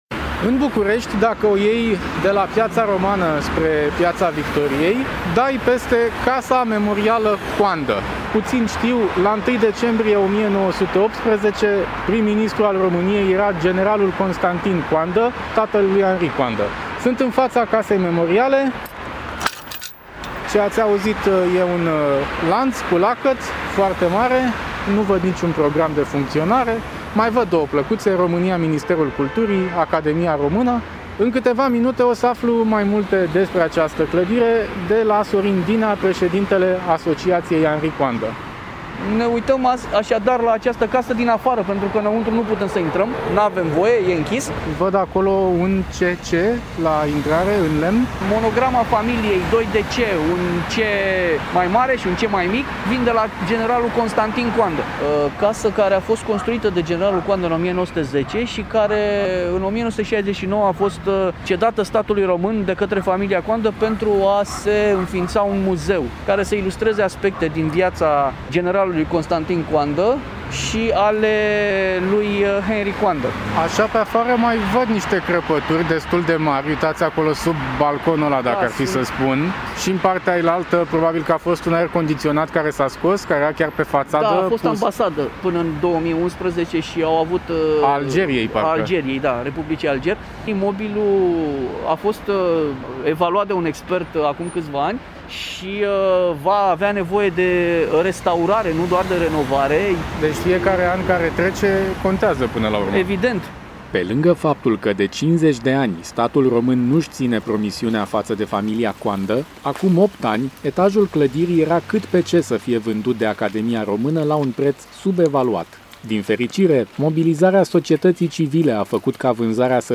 Ascultăm un reportaj